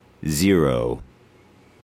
描述：与科幻相关的口头文本样本。
Tag: 语音 英语 科幻 美国航空航天局 电火花 声乐 口语 空间